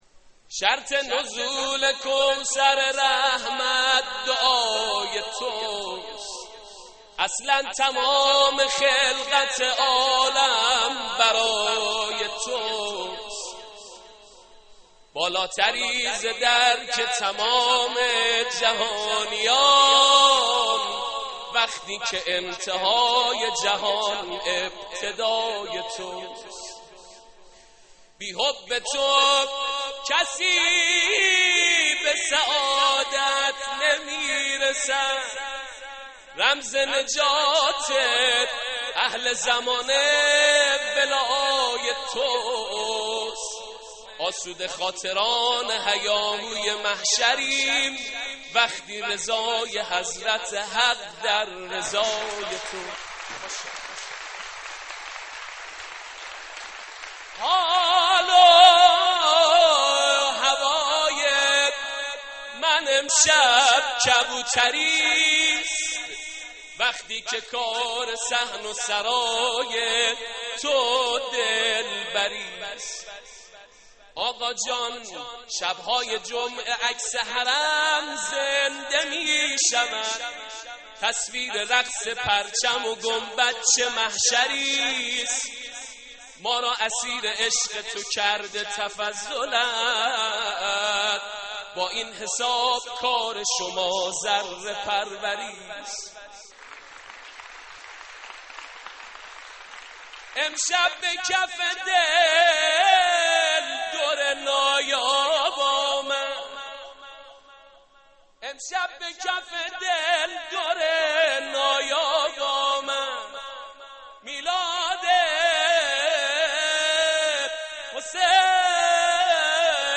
مولودی ویژه ولادت امام حسین علیه‌السلام